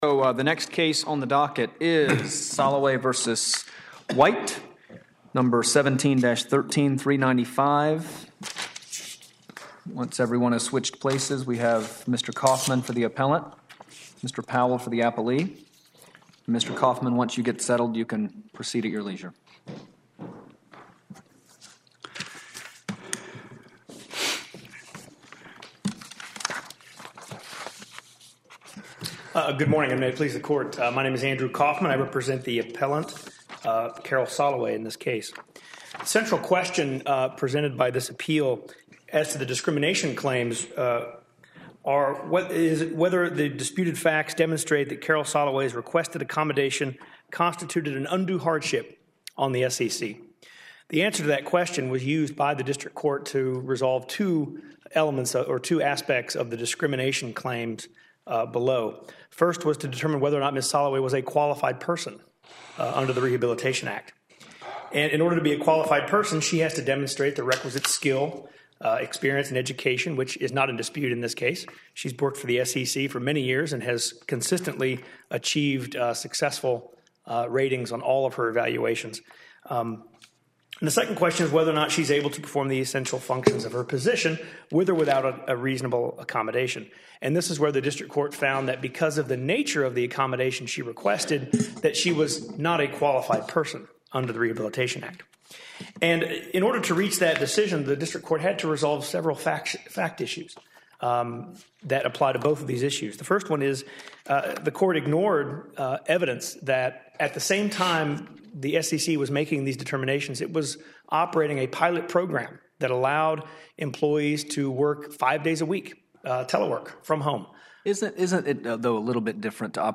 Oral Argument Recordings | Eleventh Circuit | United States Court of Appeals